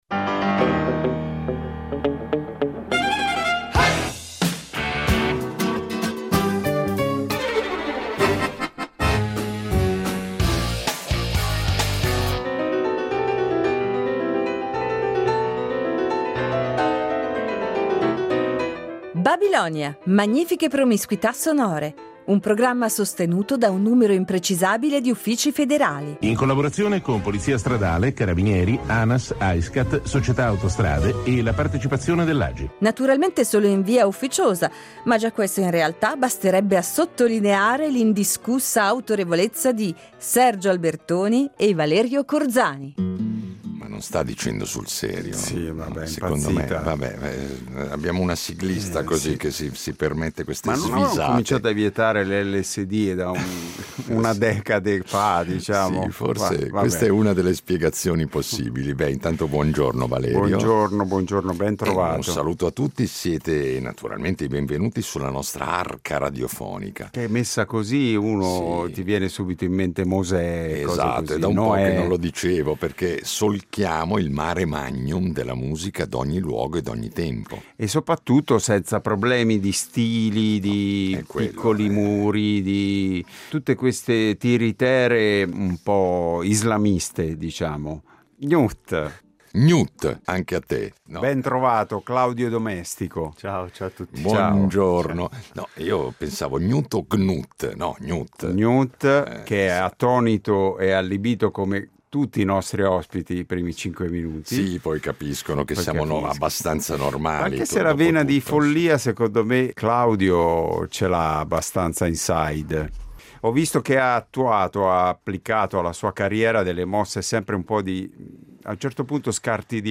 Il nostro ospite di oggi è un artista napoletano classe ’81, cantautore, chitarrista, produttore e compositore di colonne sonore.